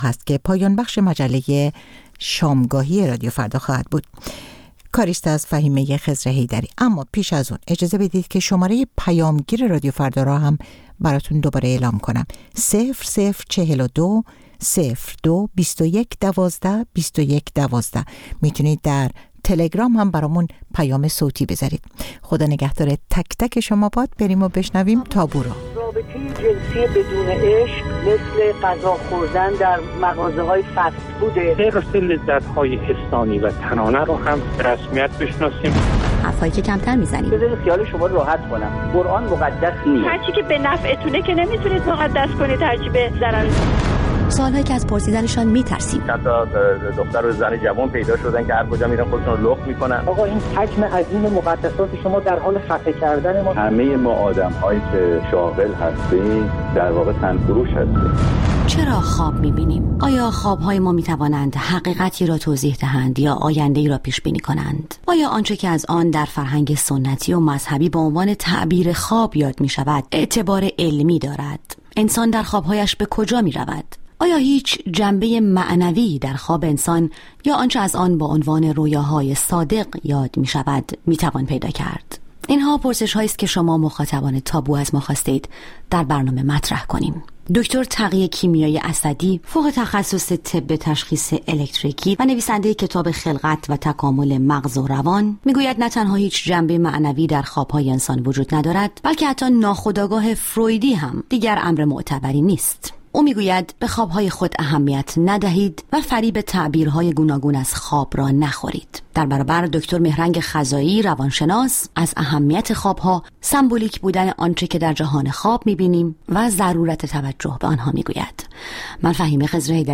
با دو‌ مهمانِ برنامه درباره‌ی موضوعاتی که اغلب کمتر درباره‌شان بحث و گفت‌وگو کرده‌ایم به مناظره می‌نشیند. موضوعاتی که کمتر از آن سخن می‌گوییم یا گاه حتی ممکن است از طرح کردن‌شان هراس داشته باشیم.